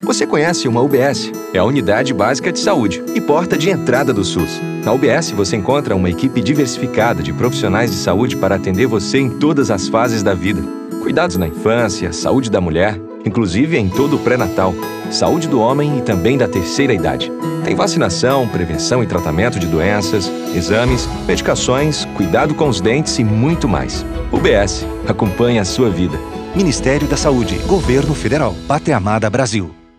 Spot - Atenção Primária Saúde.wav — Ministério da Saúde
aps-spot-30-segundos.wav